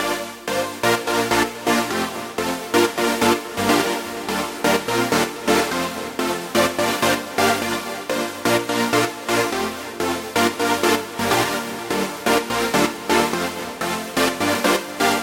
灰暗的合成树脂垫循环播放01
描述：现代吉他合成器，振奋人心的声音
标签： 126 bpm Grime Loops Synth Loops 2.56 MB wav Key : Unknown
声道立体声